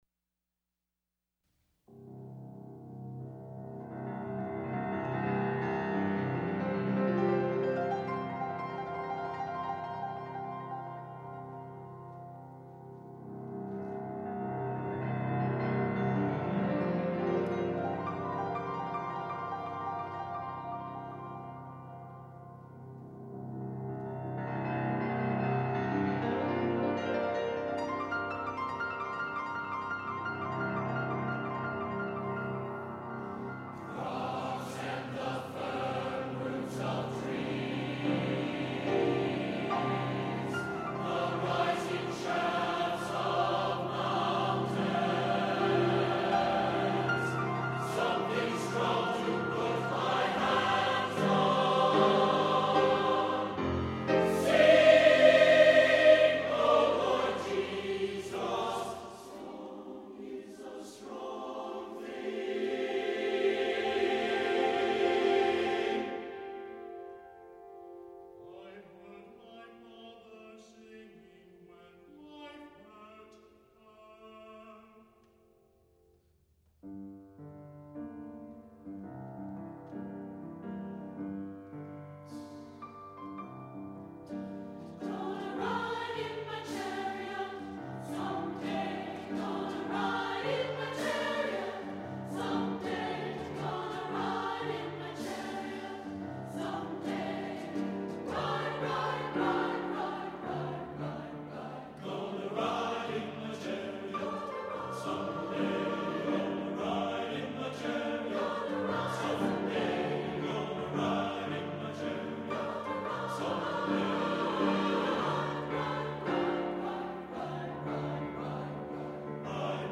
for SATB Chorus and Piano (2000)